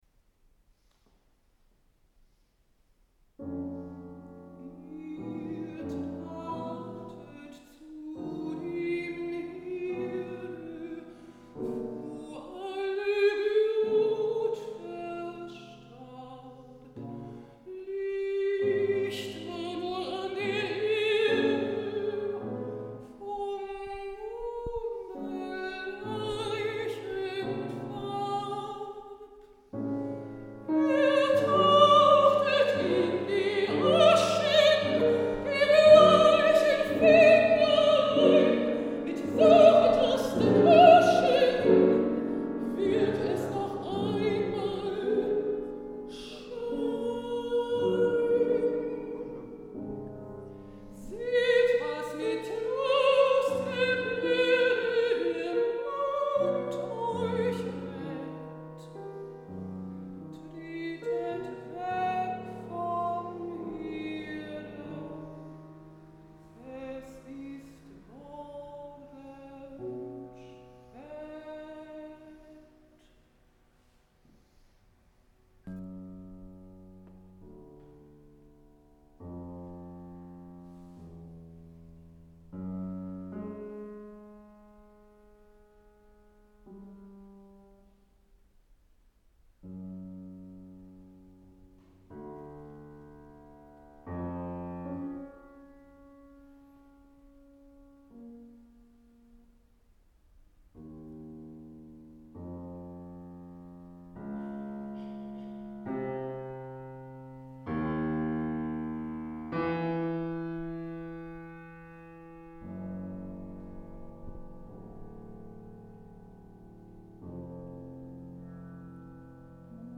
Mitschnitt eines Liederabends
Juli 1994 im Sorbischen Museum Bautzen
Mezzosopran
Klavier